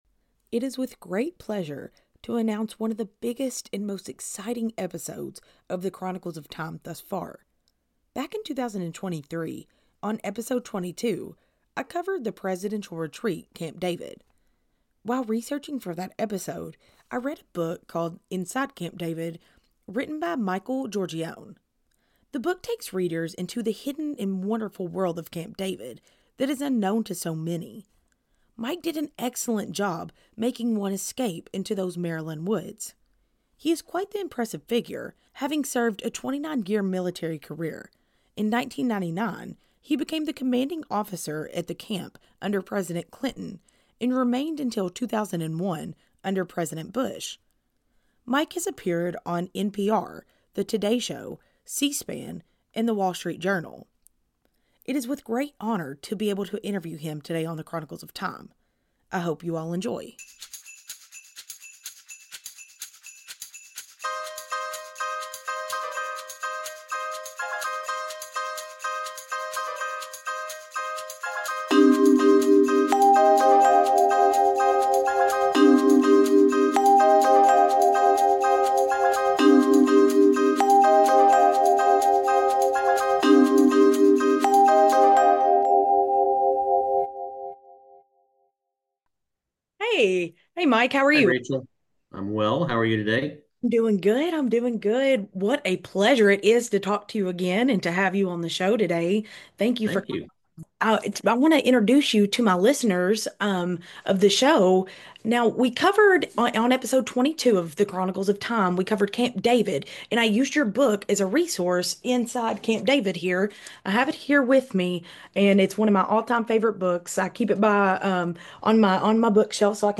SPECIAL EPISODE: Interview